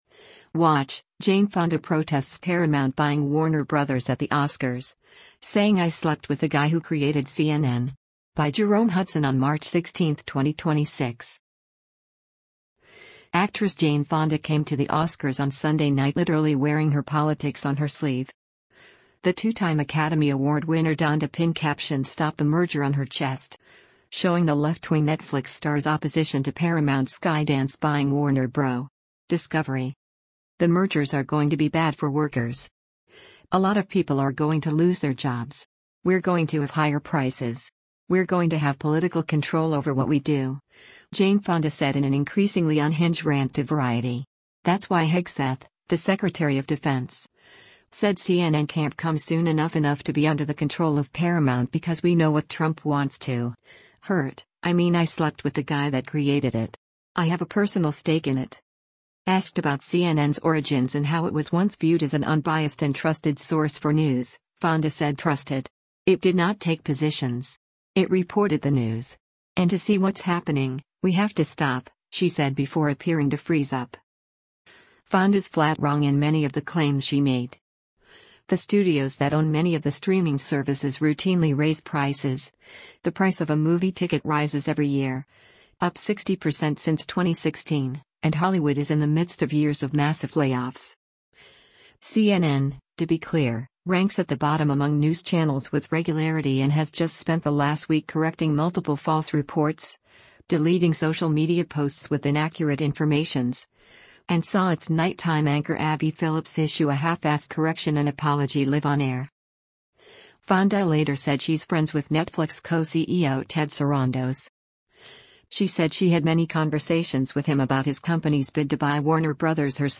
Asked about CNN’s origins and how it was once viewed as an unbiased and “trusted” source for news, Fonda said “Trusted! It did not take positions. It reported the news. And to see what’s happening….we have to stop,” she said before appearing to freeze up.
“In order to get the permission to do the merger, they had to cave to what Trump wanted,” she said before her speech tailed off again, adding “But, we’re going to win. We’re going to win.”